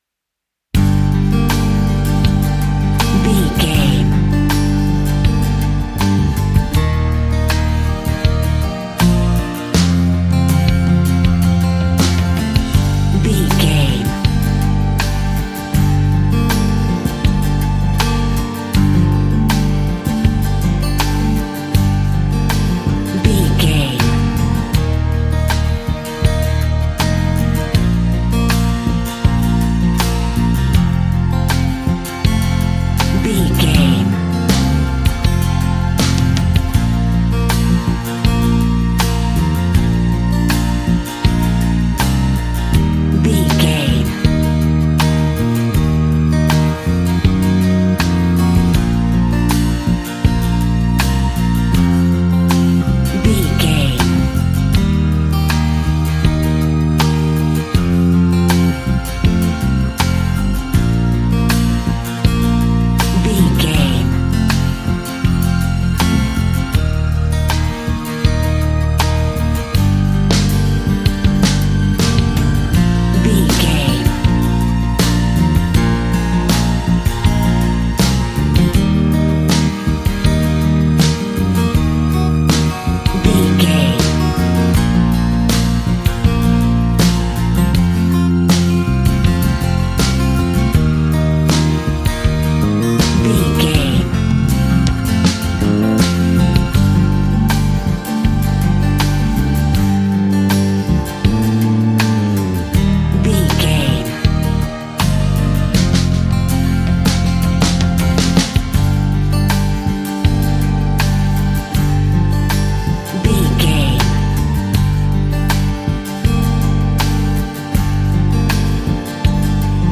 Ionian/Major
B♭
sweet
happy
acoustic guitar
bass guitar
drums